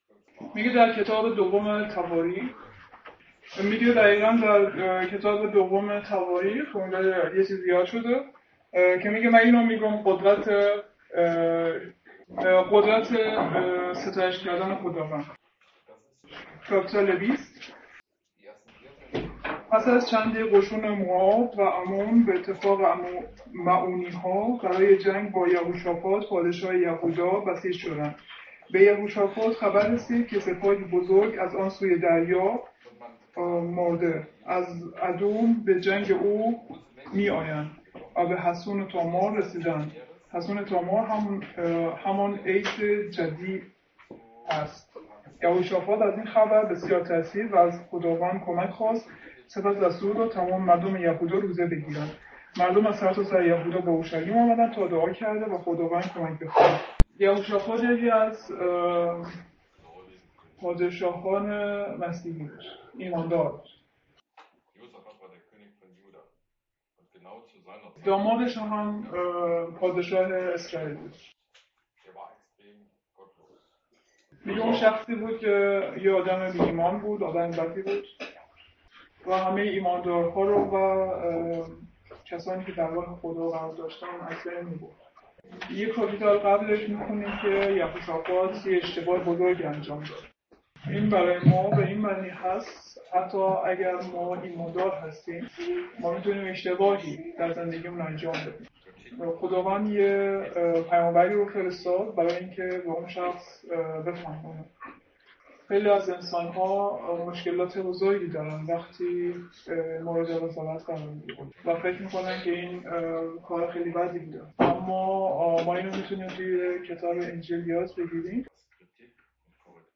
Übersetzung der Predigt in Farsi